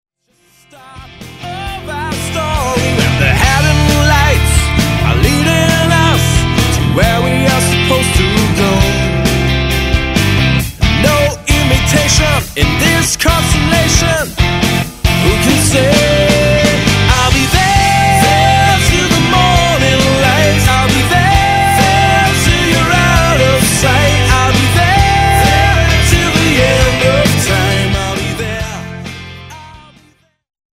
Melodische Rockmusik mit Energie und Ohrwurmgarantie
vocals/guitar
bass/backing vocals
drums
keyboards